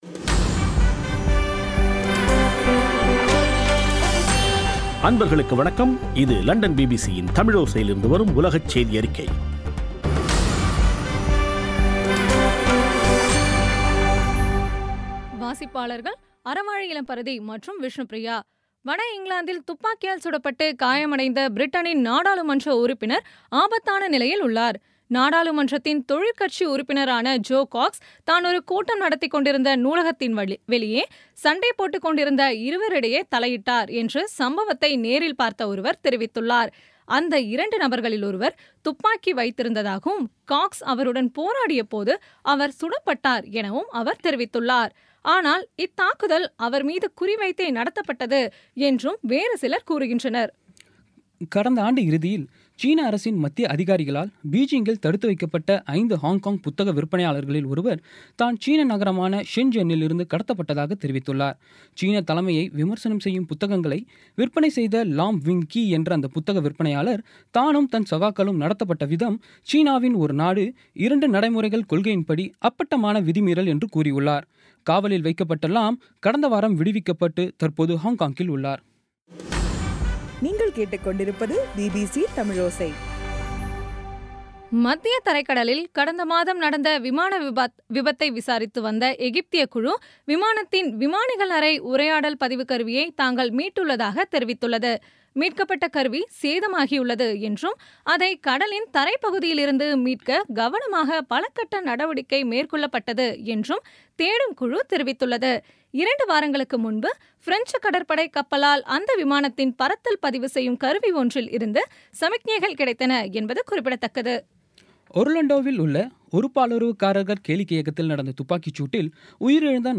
இன்றைய (ஜூன் 16ம் தேதி ) பிபிசி தமிழோசை செய்தியறிக்கை